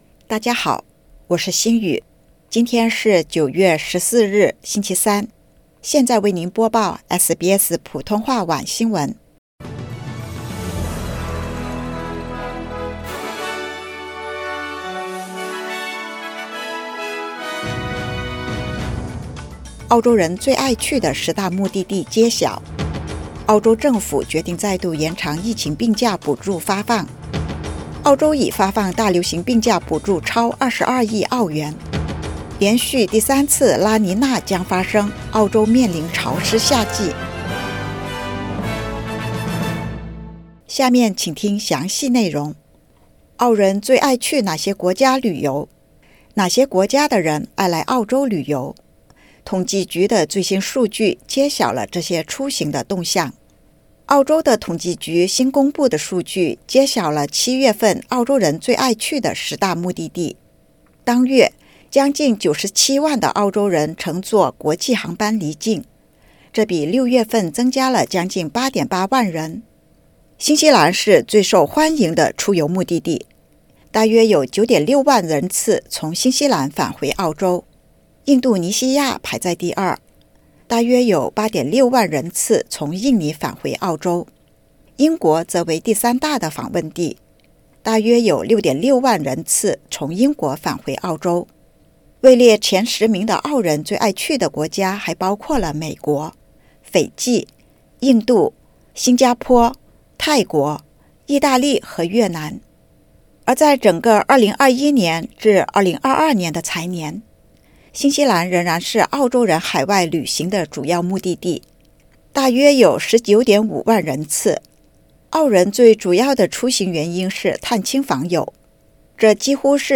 SBS晚新闻（2022年9月14日）